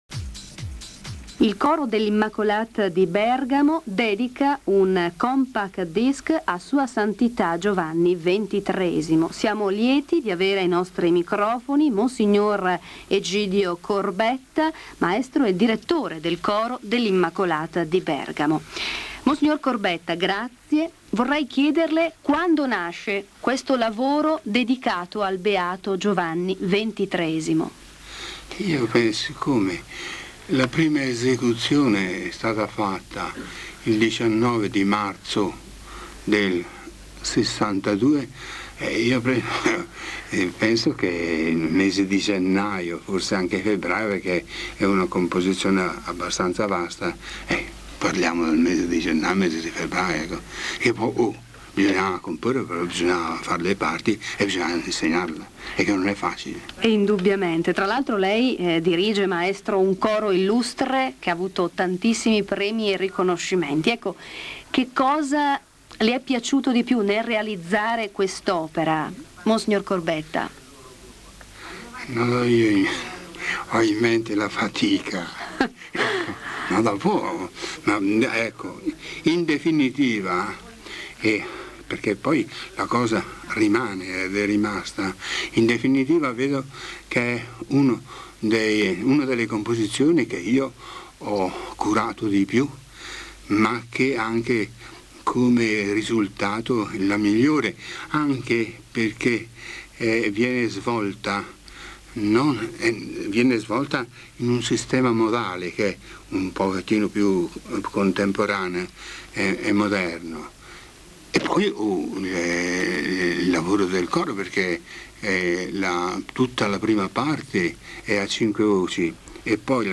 Le interviste
Intervista radiofonica